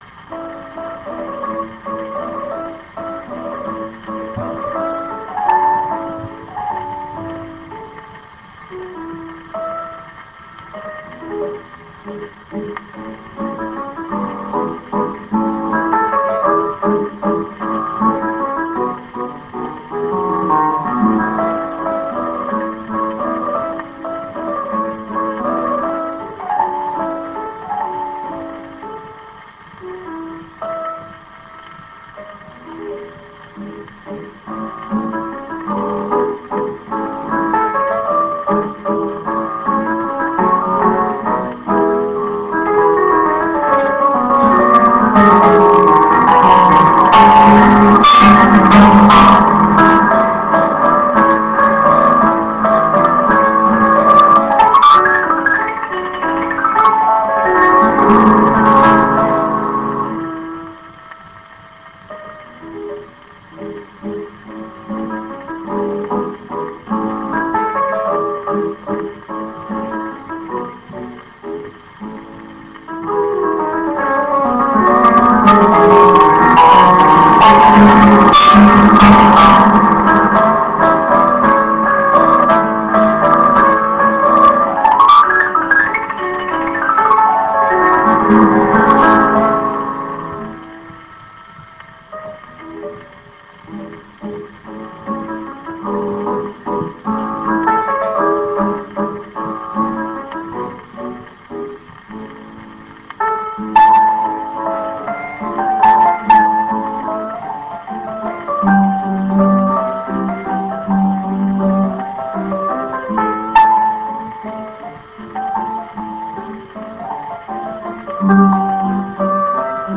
蓄音機から流れる音を録音してみました!!
ノスタルジックな雑音混じりの音楽を
ピアノ*ソロ
12インチSP盤